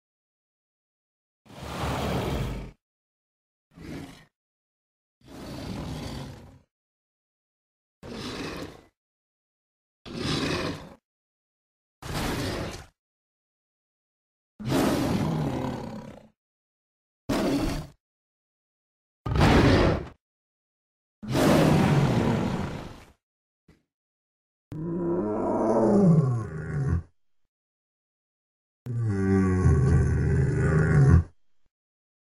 Звуки саблезубого тигра
Рев саблезубого тигра